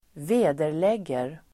Ladda ner uttalet
Uttal: [²v'e:der_leg:er]